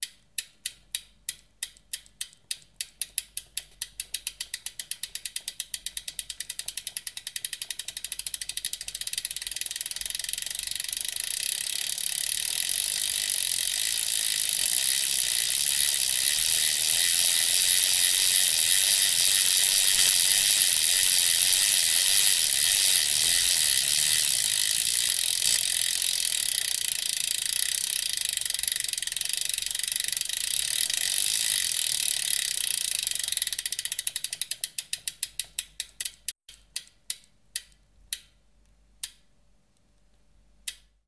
Fahrrad-Audios
freilauf_campa.mp3